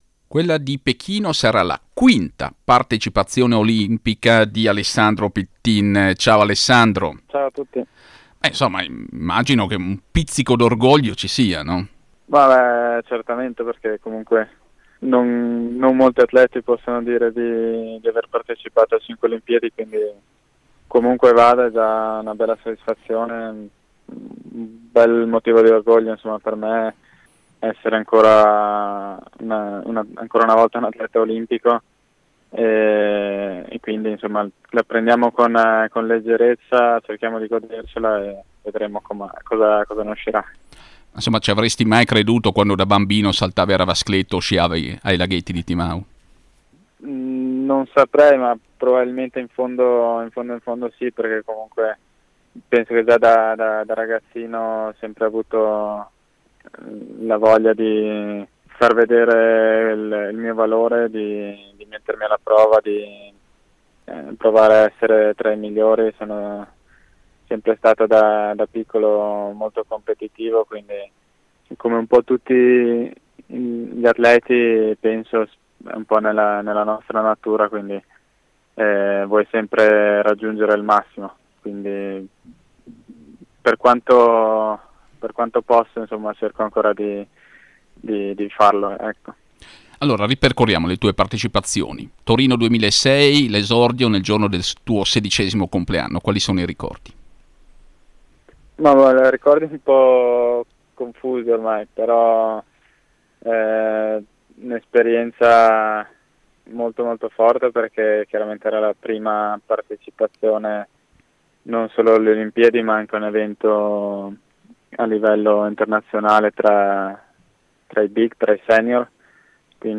Audio intervista al finanziere di Cercivento, da pochi mesi padre, in gara mercoledì nella combinata nordica